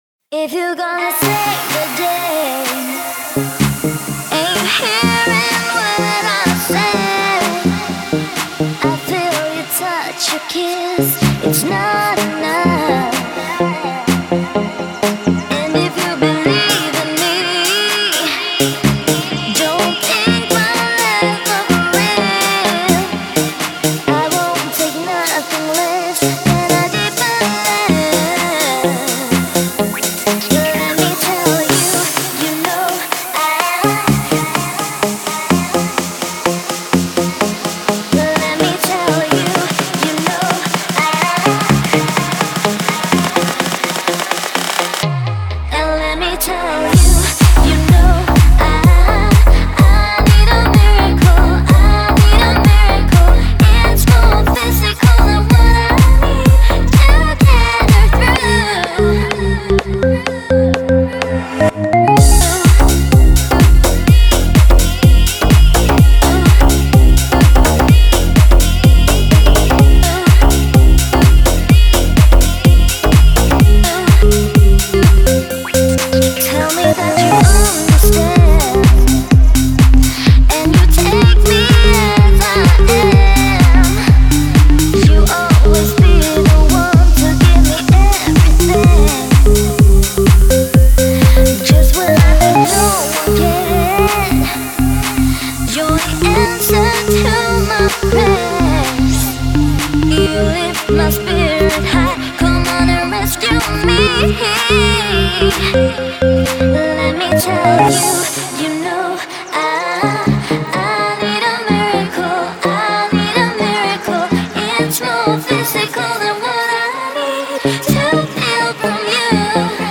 это энергичная и зажигательная песня в жанре хаус